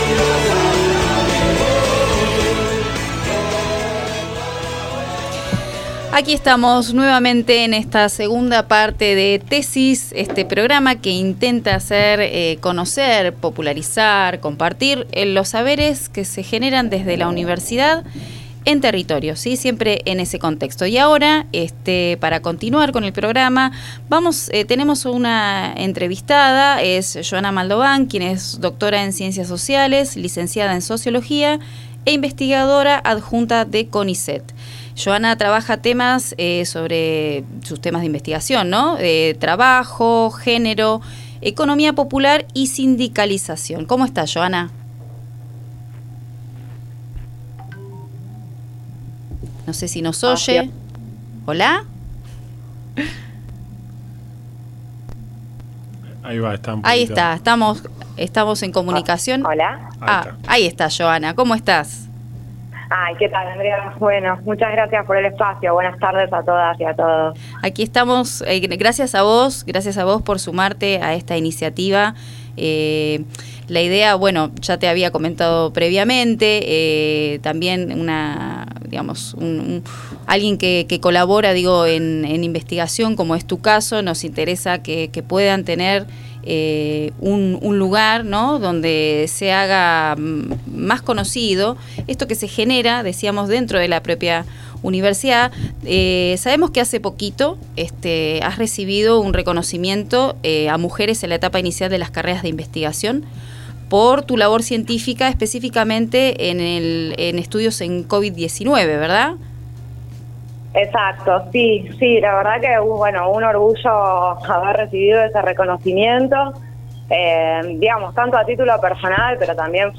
Entrevista